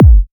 Index of /90_sSampleCDs/Club_Techno/Percussion/Kick
Kick_01.wav